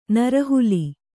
♪ nara huli